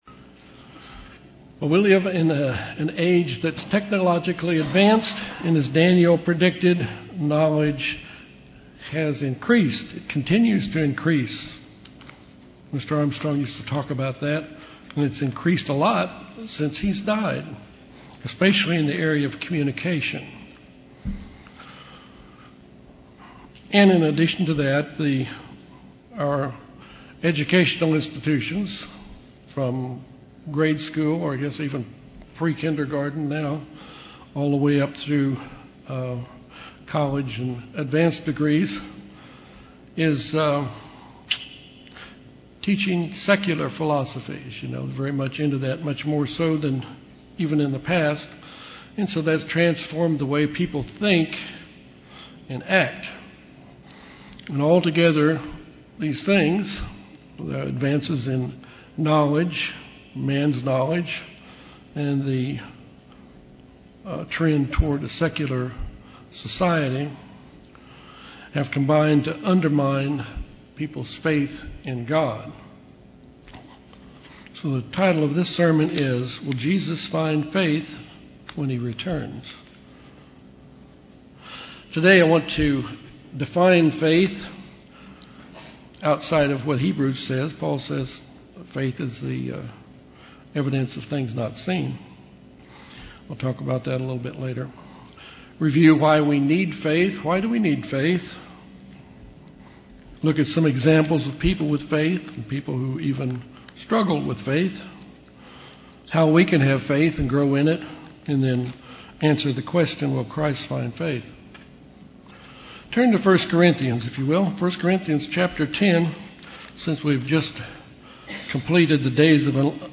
When Jesus Christ returns, He will find a faithful remnant of His church and His people. This sermon talks about our need to have faith in God: what it is, why we need it, how we grow in it, examples of faith, and things that undermine faith.